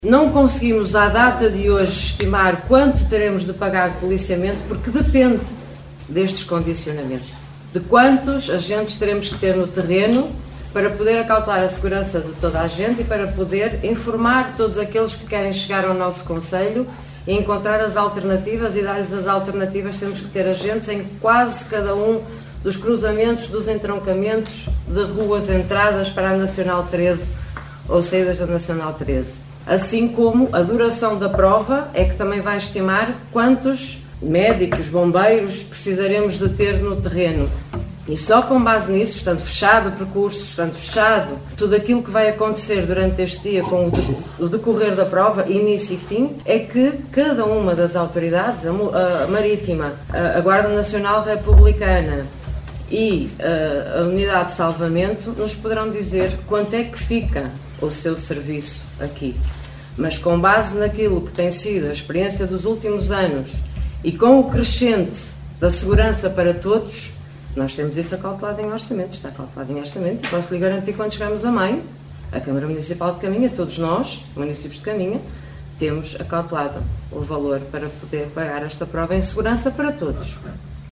Excertos da última reunião de câmara, no passado dia 7 de Fevereiro, onde foram aprovados os apoios financeiros à Associação Triatlo de Caminha para a realização do Triatlo Longo, Meia Maratona e Corrida de São Silvestre, cujos percursos e condições se mantêm, segundo os protocolos, idênticos às edições dos anos anteriores.